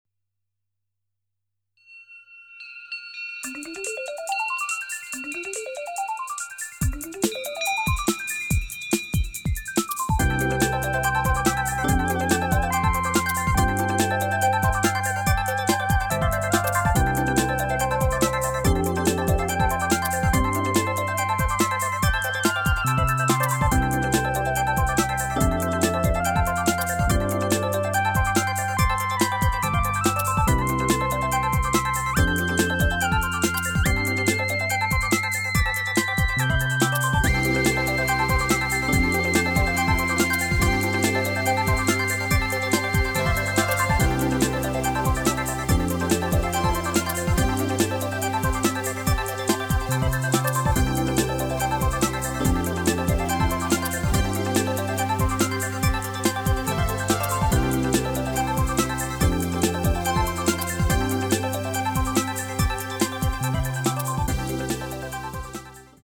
・・・なんかずれてる、かも。